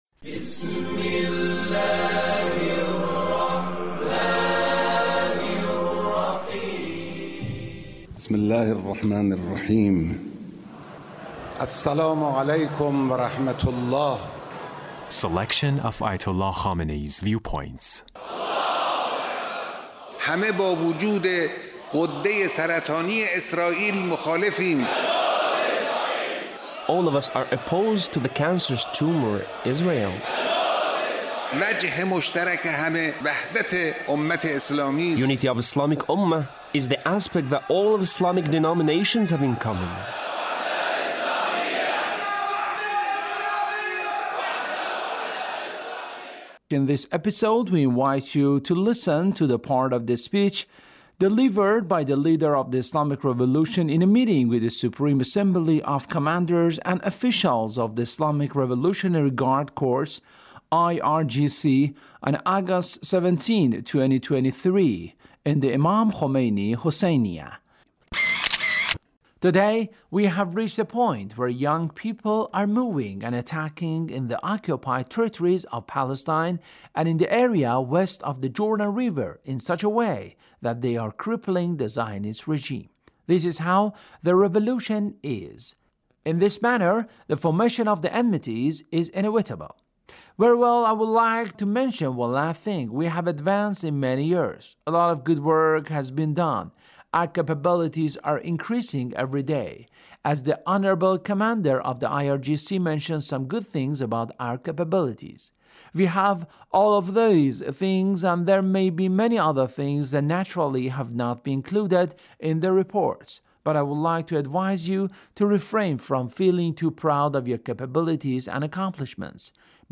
Leader's Speech (1824)
Leader's Speech in a meeting with Revolution in a meeting with the Supreme Assembly of Commanders and Officials of the Islamic Revolutionary Guard Corps (...